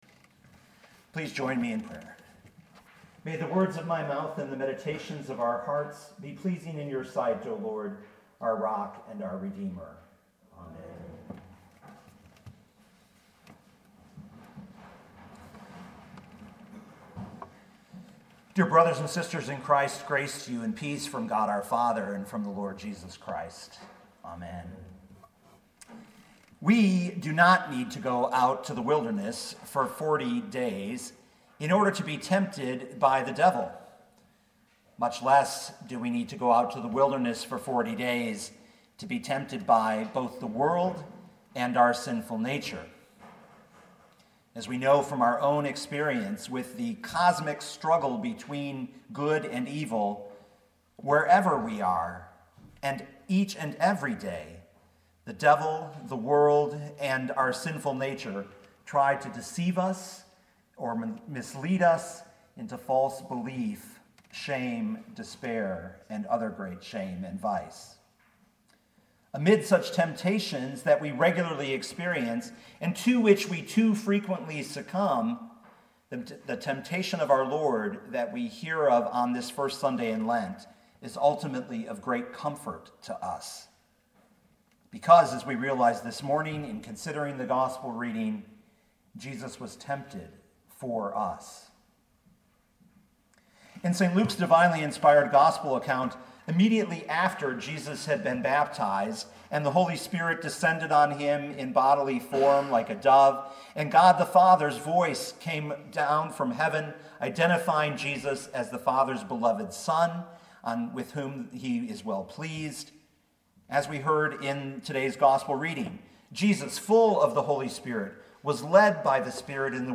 2019 Luke 4:1-13 Listen to the sermon with the player below, or, download the audio.
1st-sunday-in-lent.mp3